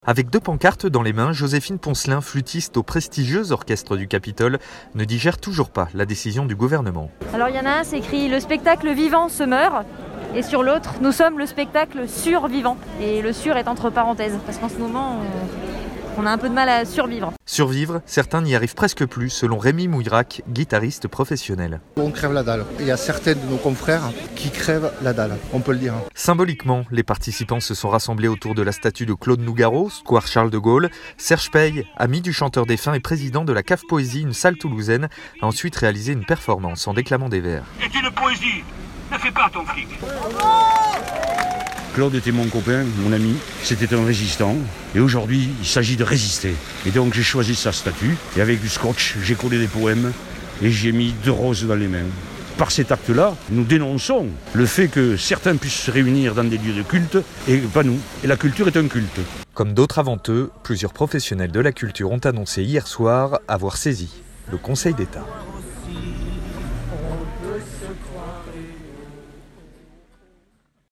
Un reportage
ENROBE-manif-culture.mp3